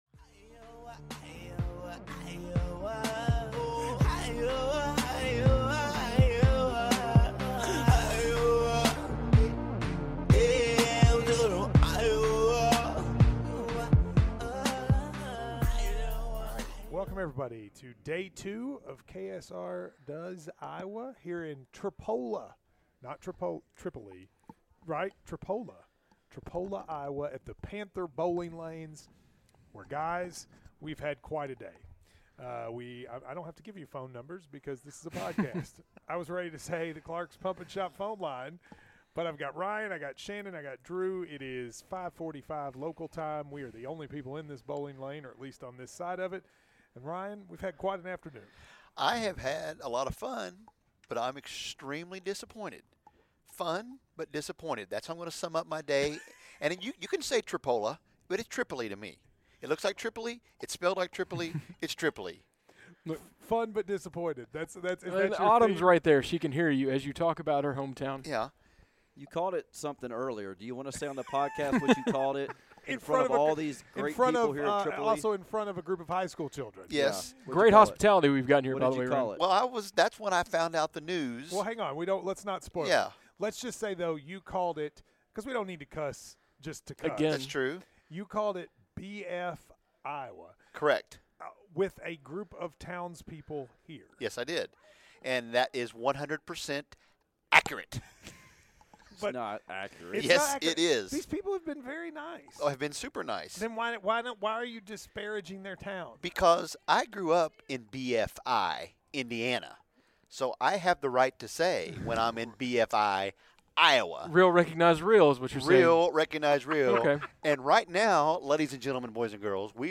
KSR brought the ridiculousness to Iowa for the caucuses and the second episode of the 'KSR Takes Iowa' podcast was recorded at a bowling alley where John Delaney was a no show.